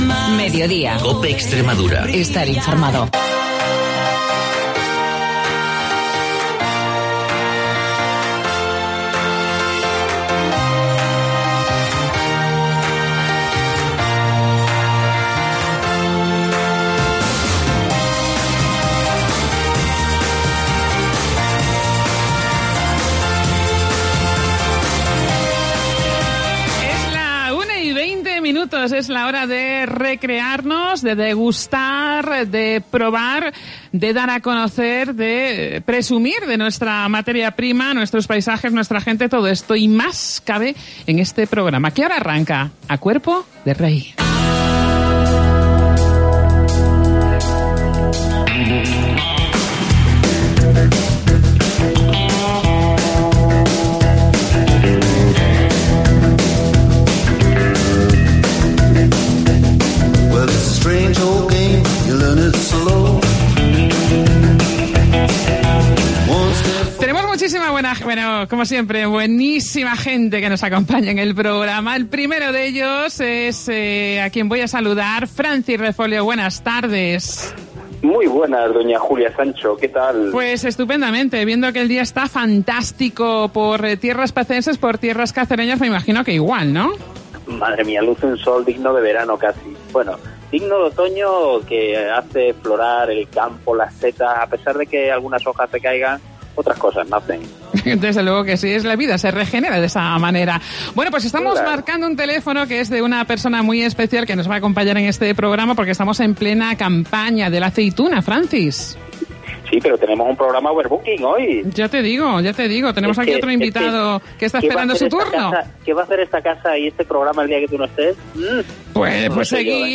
Nos encantan estas cosas que pasan en el directo.